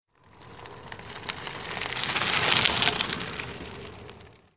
دانلود آهنگ دوچرخه 12 از افکت صوتی حمل و نقل
جلوه های صوتی
دانلود صدای دوچرخه 12 از ساعد نیوز با لینک مستقیم و کیفیت بالا